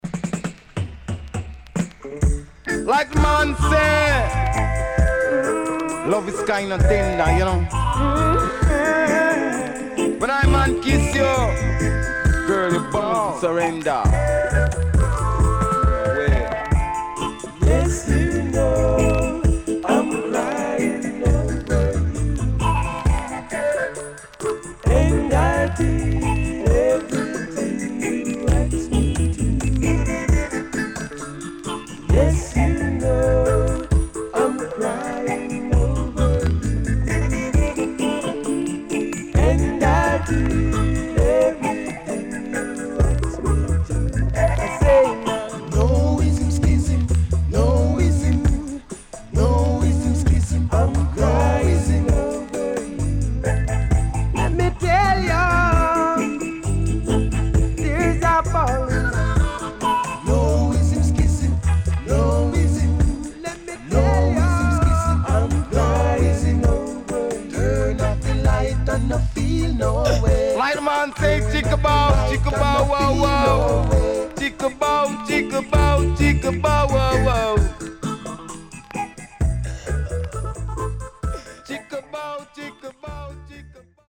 SIDE A:全体的にチリノイズがあり、少しプチノイズ入ります。
SIDE B:全体的にチリノイズがあり、少しプチノイズ入ります。